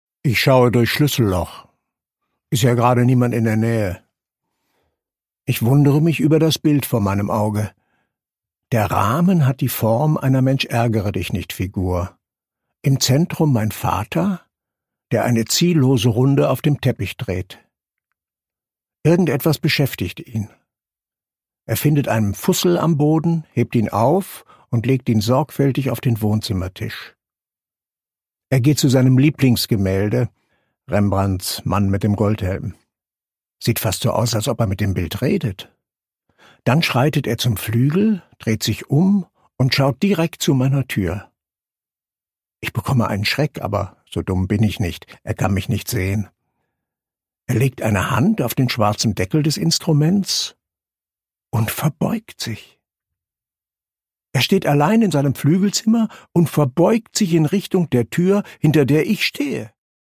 Produkttyp: Hörbuch-Download
Gelesen von: Edgar Selge